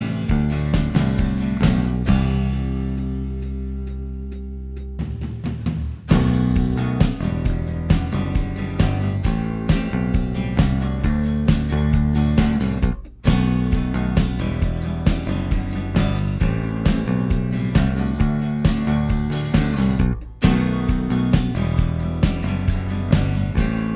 Minus Electric Guitar Pop (1980s) 3:23 Buy £1.50